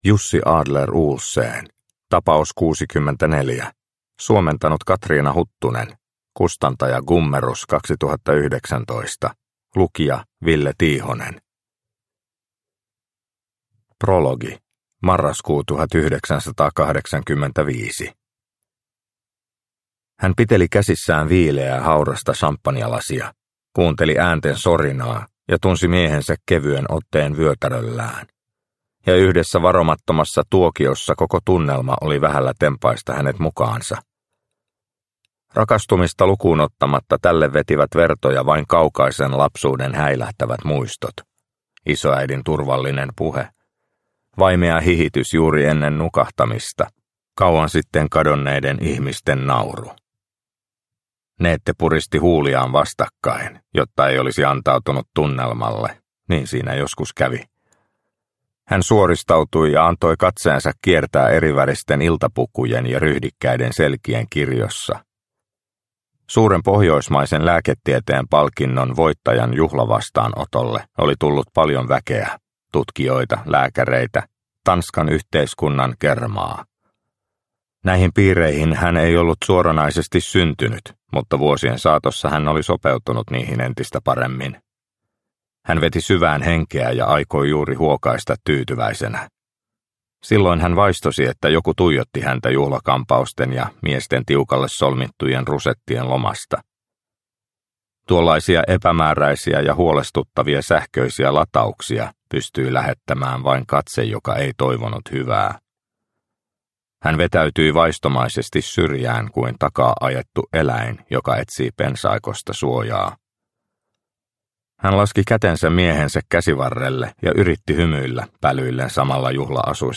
Tapaus 64 – Ljudbok – Laddas ner